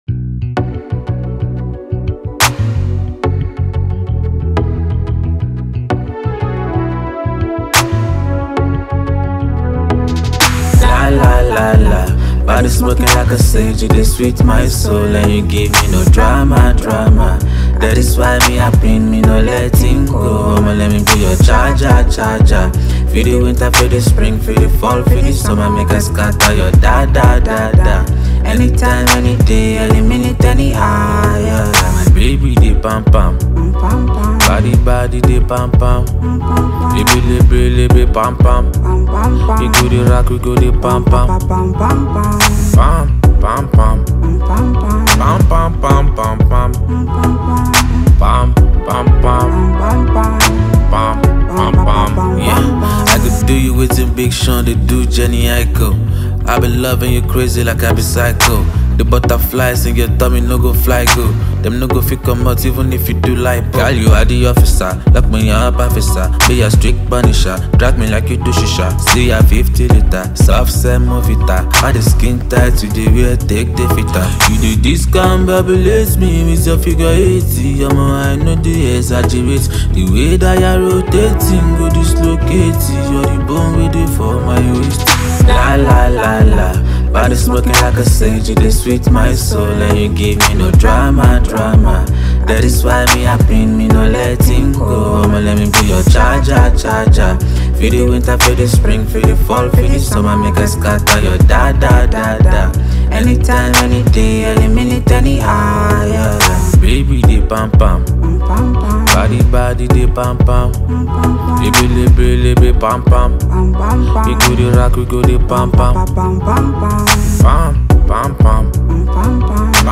All the song are made of pop sounds .